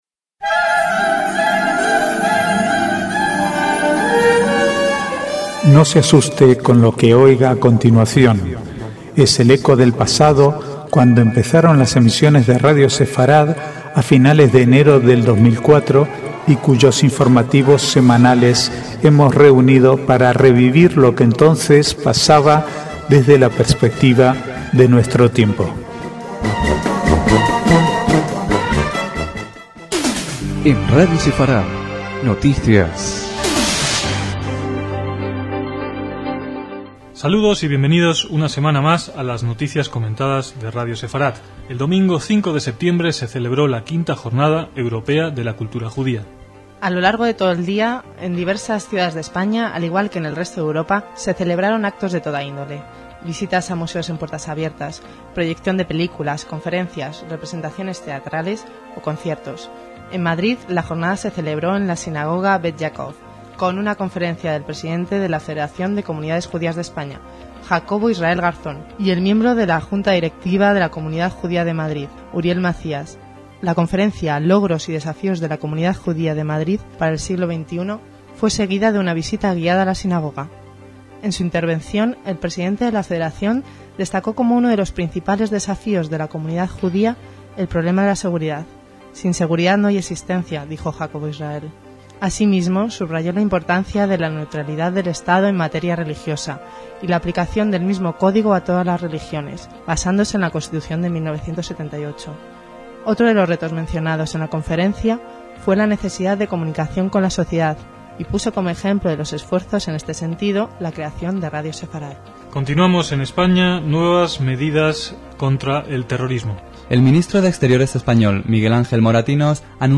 Archivo de noticias del 31/8 al 3/9/2004